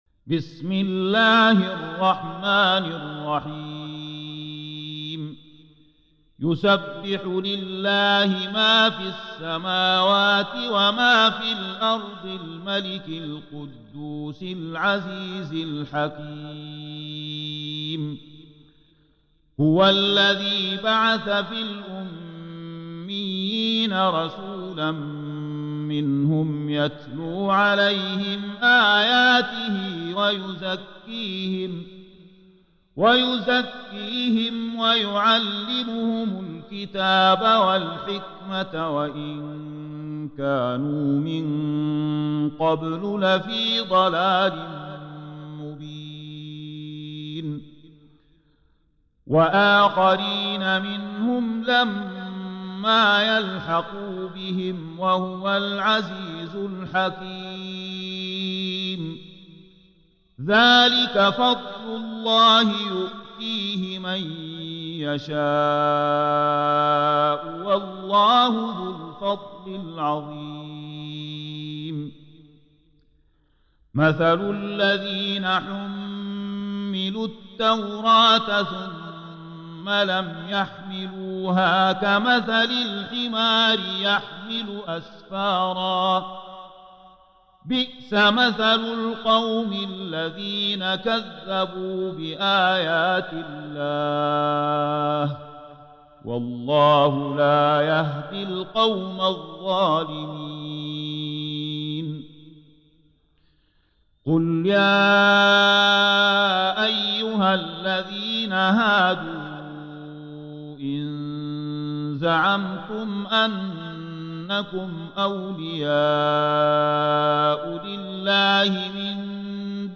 Surah Sequence تتابع السورة Download Surah حمّل السورة Reciting Murattalah Audio for 62. Surah Al-Jumu'ah سورة الجمعة N.B *Surah Includes Al-Basmalah Reciters Sequents تتابع التلاوات Reciters Repeats تكرار التلاوات